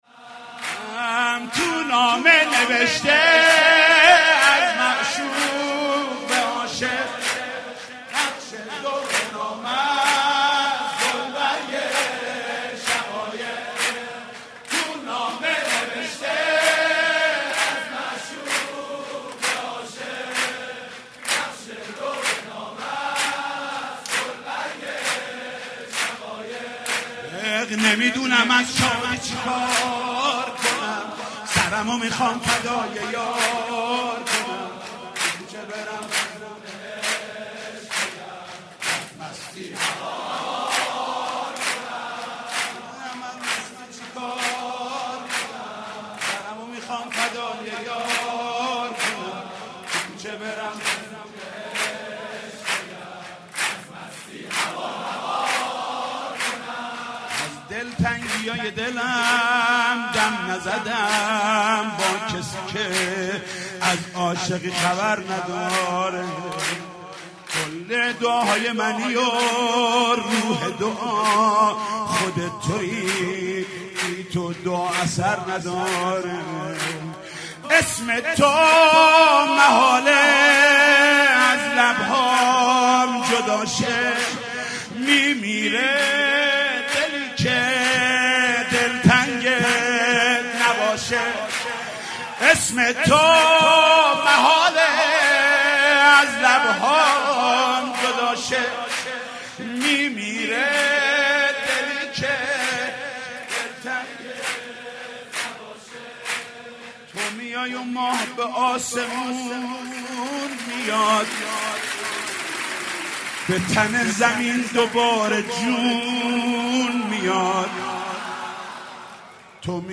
مداح اهل بیت علیهم ‌السلام